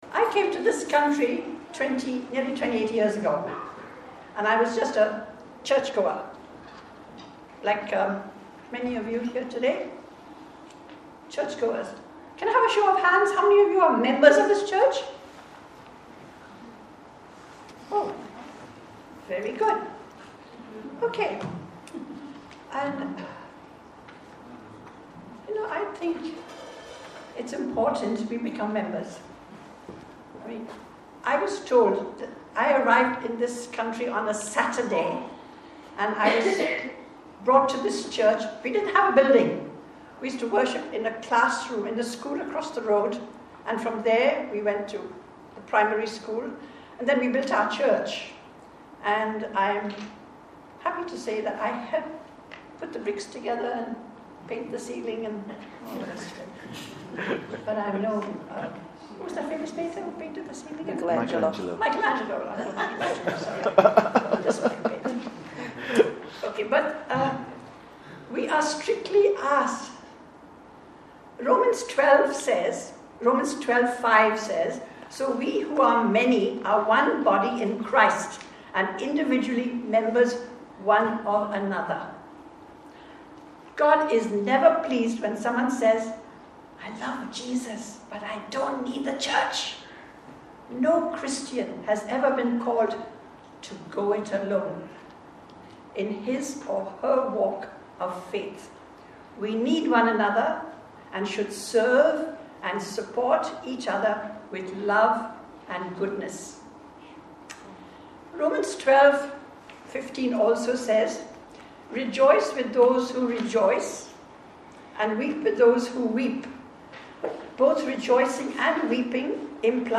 Why I Am A Member of ECCEK (Testimony)